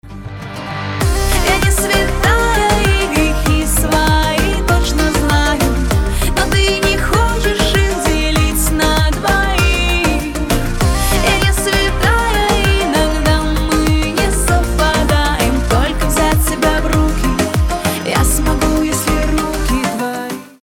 • Качество: 320, Stereo
поп
веселые